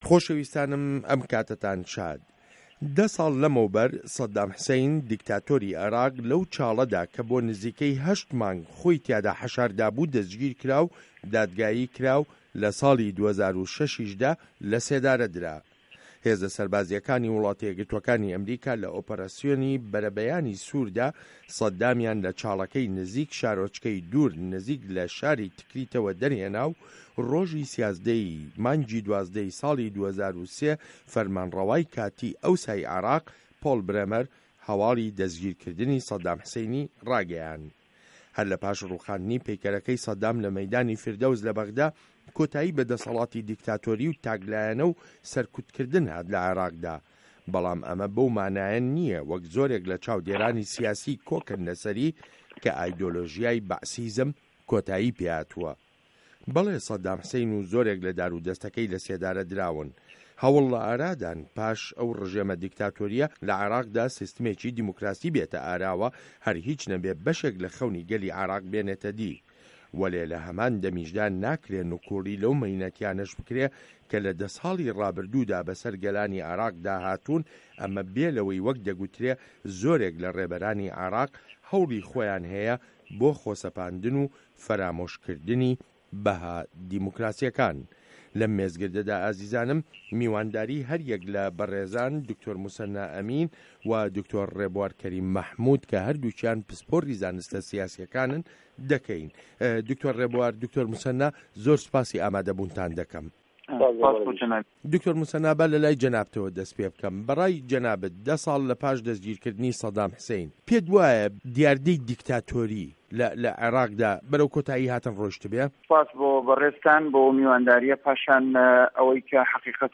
مێزگرد: سه‌دام گیرا و دیموکراسیش هه‌ر نه‌هات